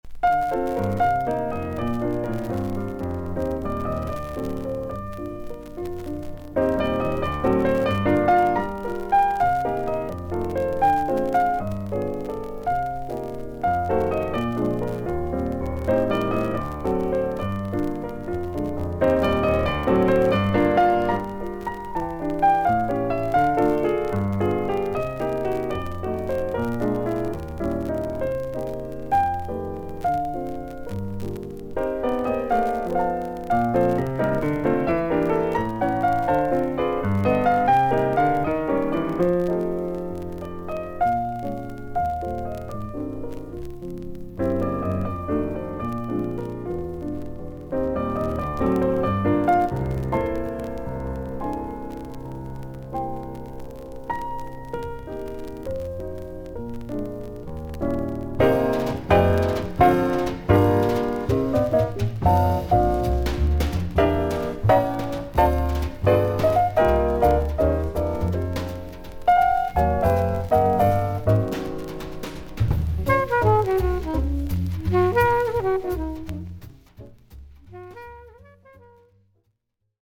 少々軽いパチノイズの箇所あり。少々サーフィス・ノイズあり。クリアな音です。
ウエスト・コーストの知性派ジャズ・ピアニスト。